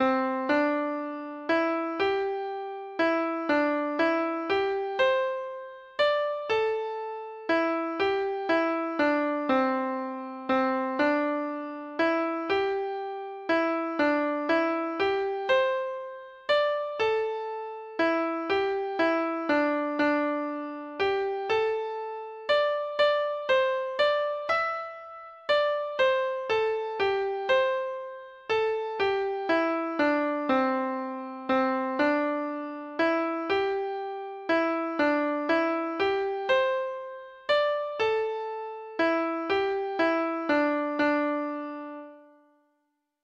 Traditional Trad. Shamrock Shore Treble Clef Instrument version
Traditional Music of unknown author.